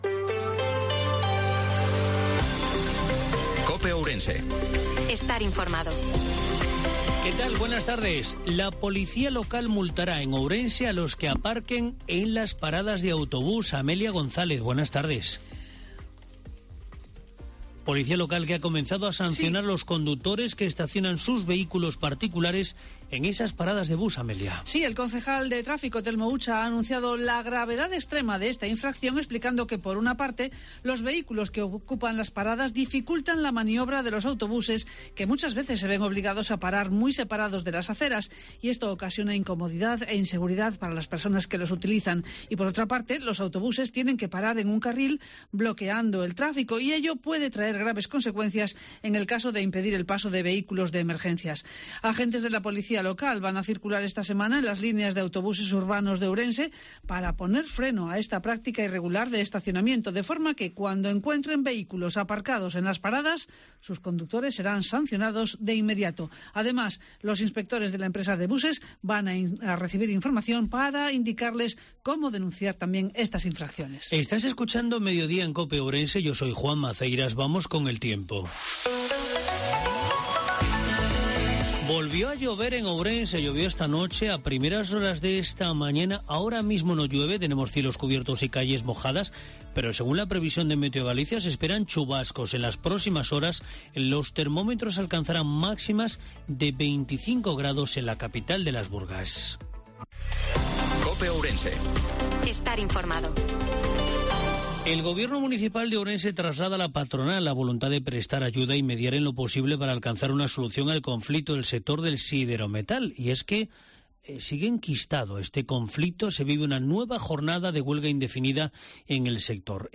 INFORMATIVO MEDIODIA COPE OURENSE-10/10/2022